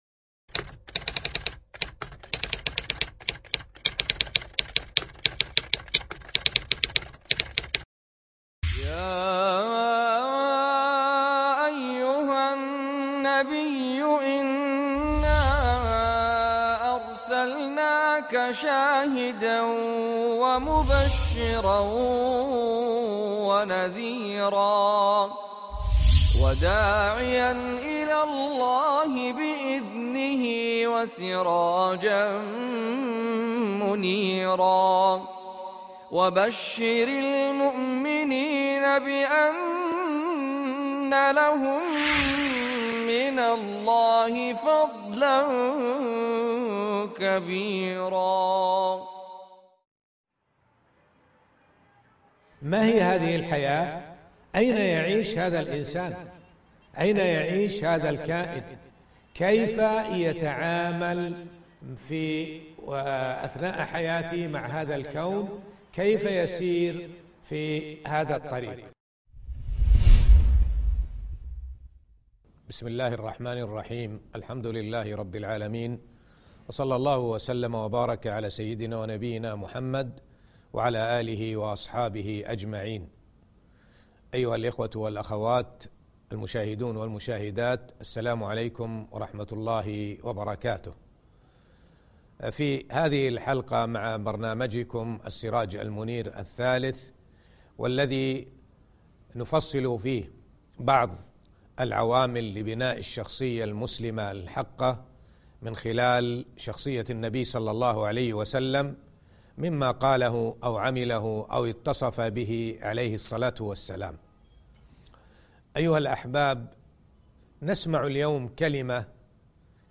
الدرس 18 ( إبداعك وتميزك ) السراج المنير 3